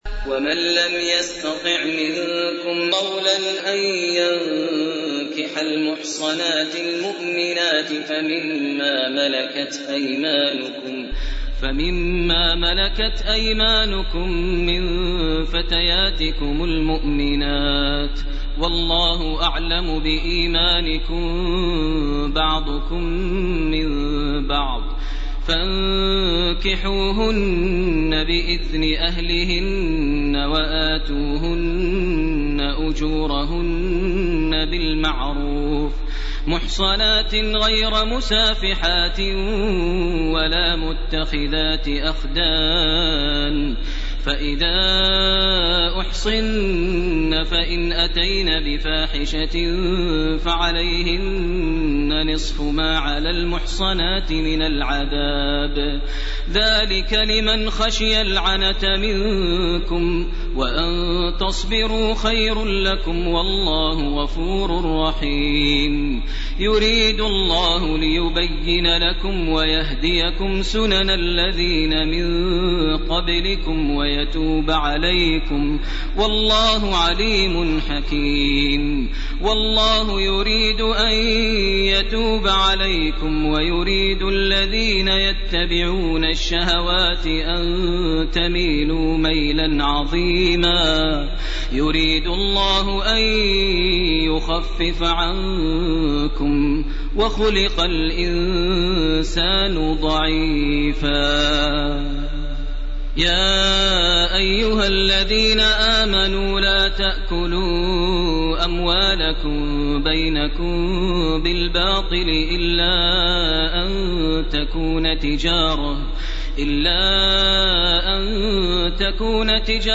سورة النساء 25 - 85 > تراويح ١٤٢٩ > التراويح - تلاوات ماهر المعيقلي